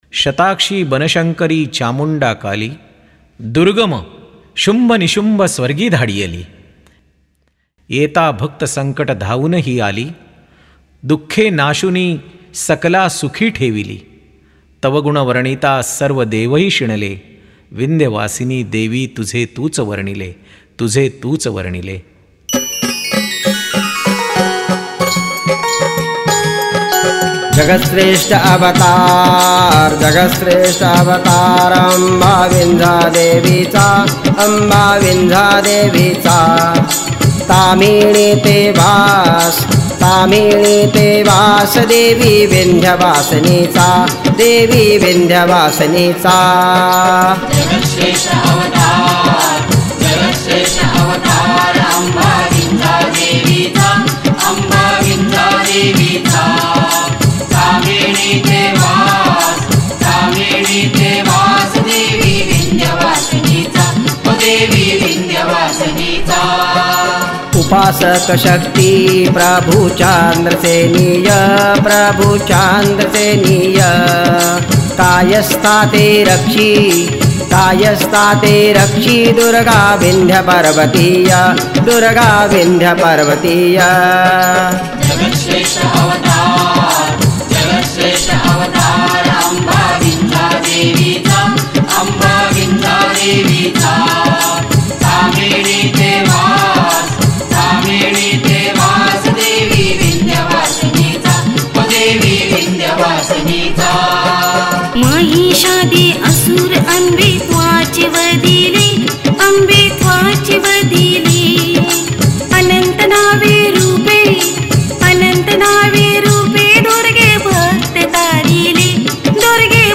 गायक
गायीका